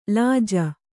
♪ lāja